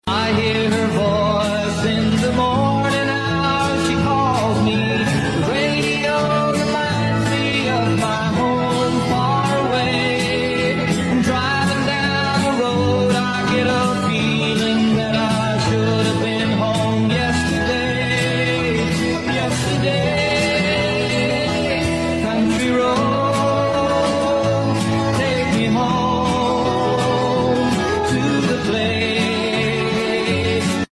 American tv Diesel powered Dodge Monster sound effects free download
American-tv Diesel powered Dodge Monster Truck doing a FREESTYLE run around the IMTHOF & Museum for the FULL THROTTLE Monster Truck event May 2025